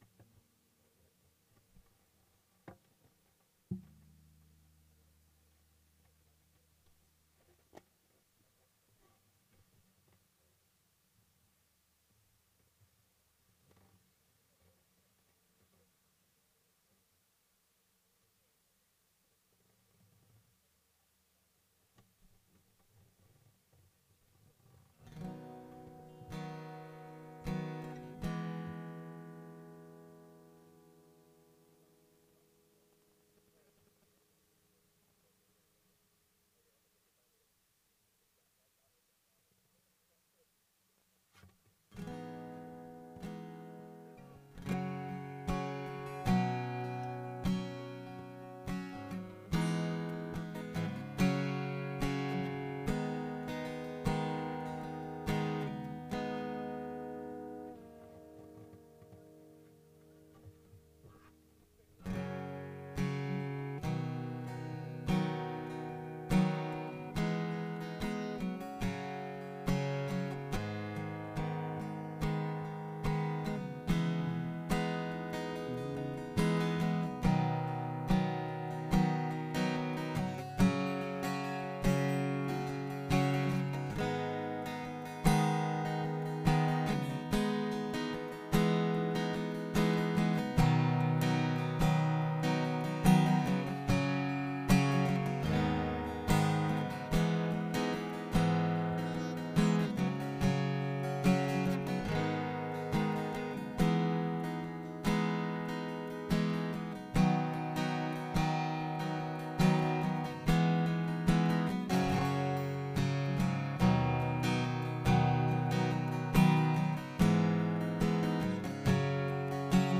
SERMON DESCRIPTION The Good Samaritan showed courage as he helped the beaten man by the road. We need courage to help the hurting people we encounter in our daily lives.